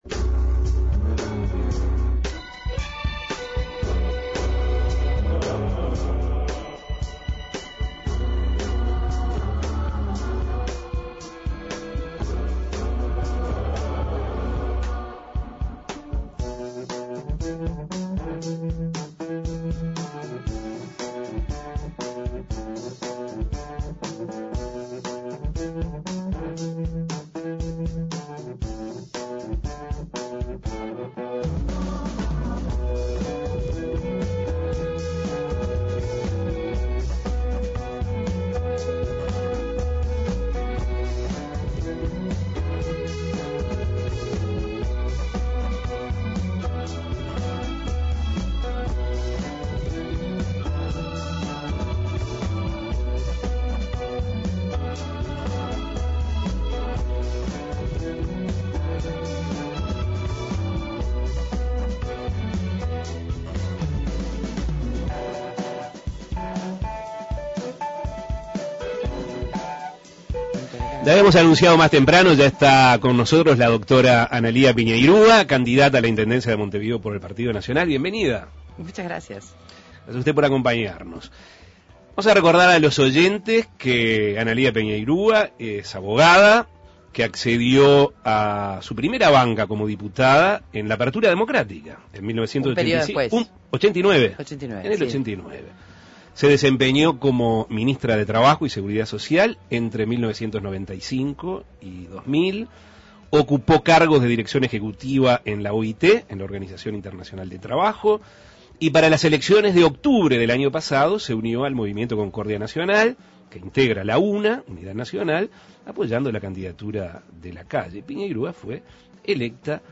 La candidata a la Intendencia de Montevideo del sector Unidad Nacional por del Partido Nacional, Analía Piñeyrúa, dialogó sobre sus inicios en la política, cómo surgió su candidatura, la campaña electoral para ocupar la jefatura comunal, cómo alternará la actividad parlamentaria con la de su campaña, el presupuesto participativo y su forma de relacionarse con el gremio municipal, entre otros temas. Escuche la entrevista.